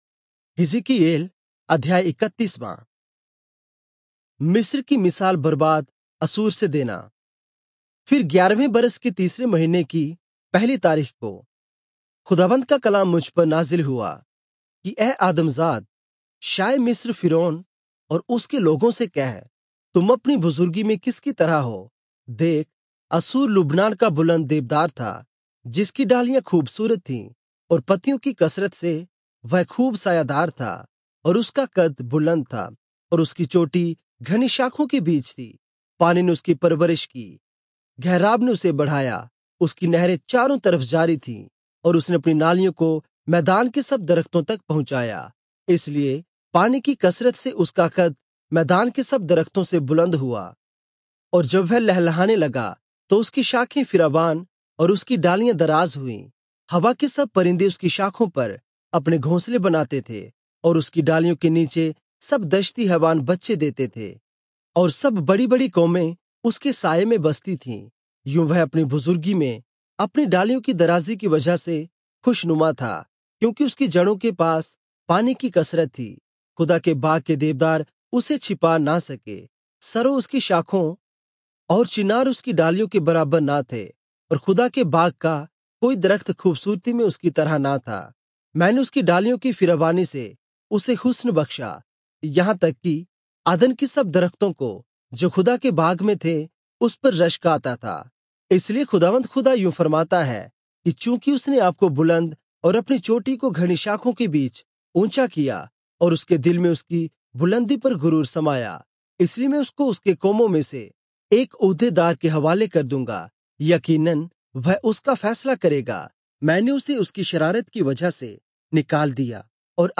Urdu Audio Bible - Ezekiel 25 in Irvur bible version